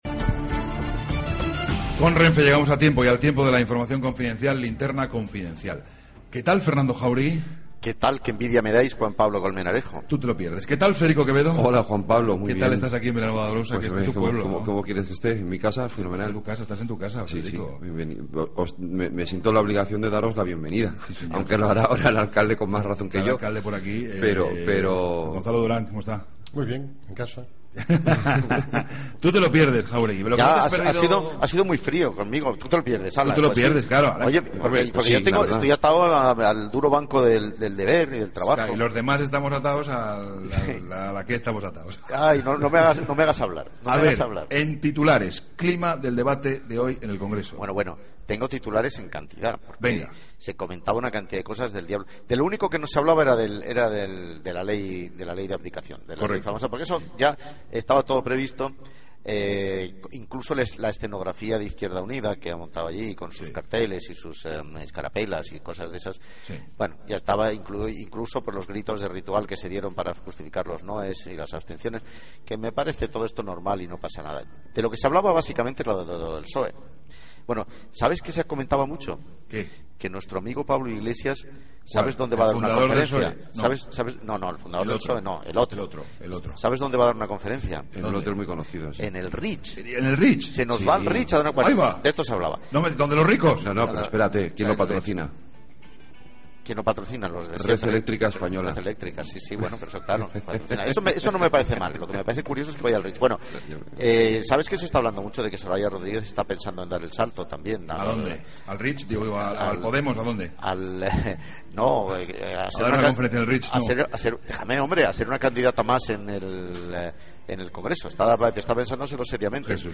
Entrevista a Gonzalo Durán, alcalde de Vilanova de Arousa